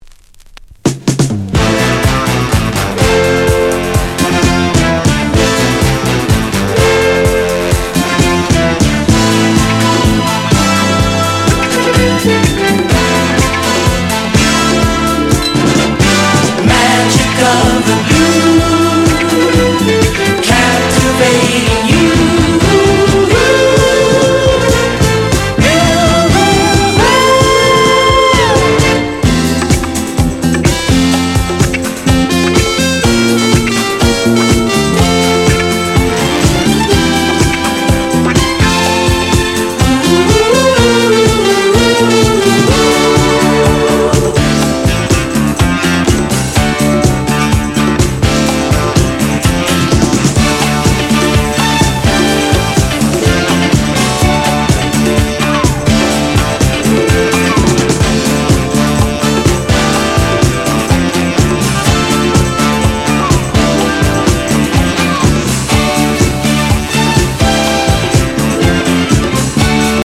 フィラデルフィアのヴォーカル・グループ
• 特記事項: STEREO / DJ